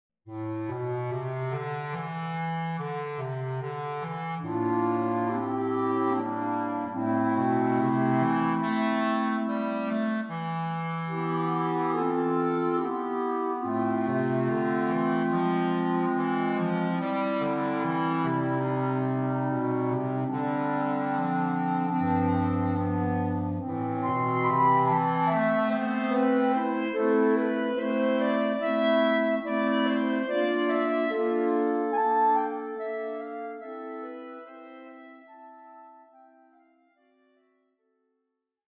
Flexible Woodwind Ensemble
Ballad excerpt (3 Clarinets and Bass Clarinet)